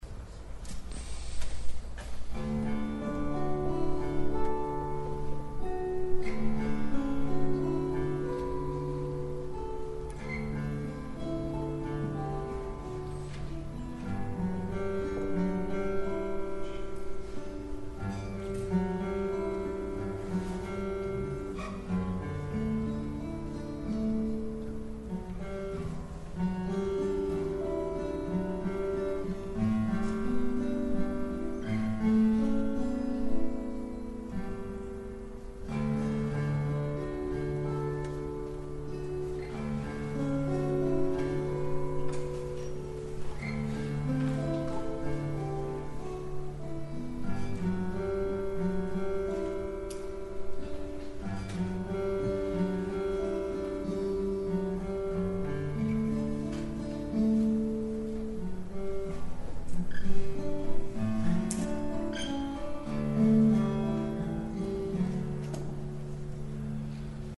These are audio clips from the 2011 convention workshop.
Identical Lutz-topped guitars with different back and sides: